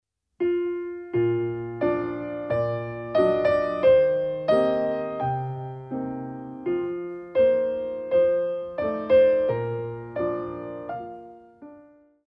Ballet Class Music For Children aged 5+